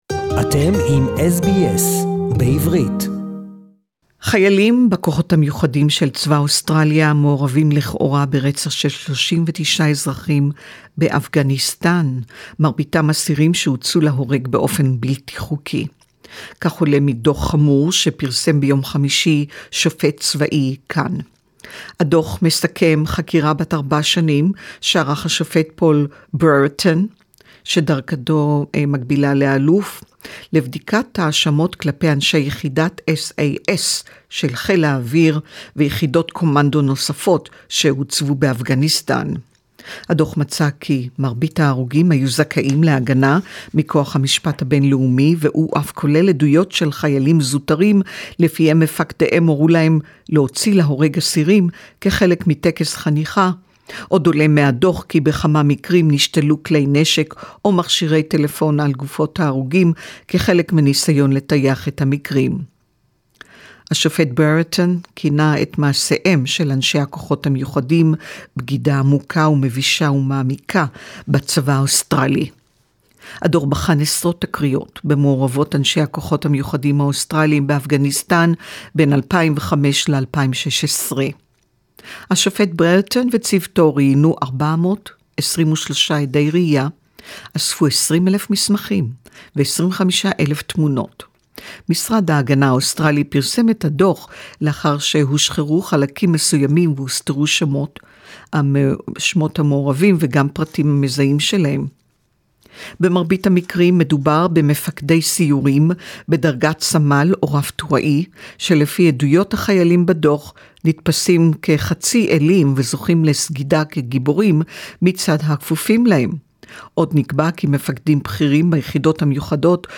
(Report in Hebrew)